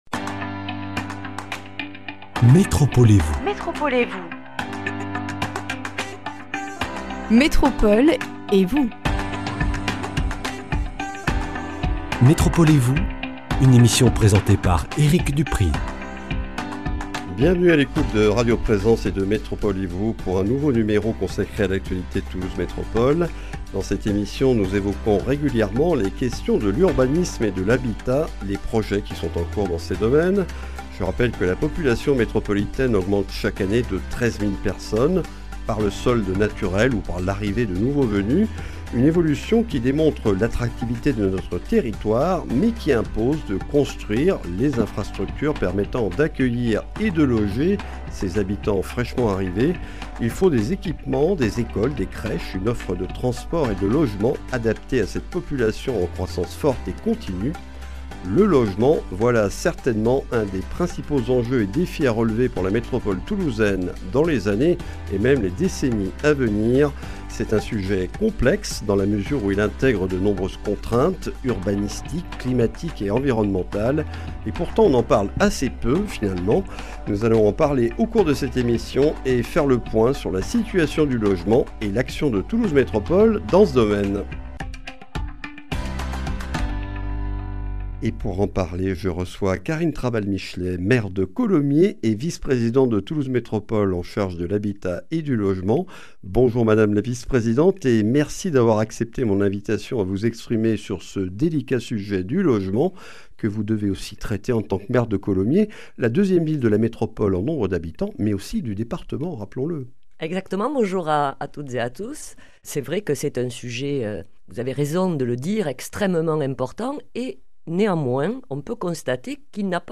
Dans une métropole dont la population augmente de 9 000 habitants chaque année, le logement est un enjeu capital pour nos élus. Nous faisons un point sur la situation et l’action de Toulouse Métropole dans ce domaine, les aides et les dispositifs qu’elle propose, avec Karine Traval-Michelet, maire de Colomiers et vice-présidente de Toulouse Métropole chargée de l’Habitat et du Logement.